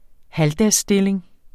Udtale [ ˈhaldas- ]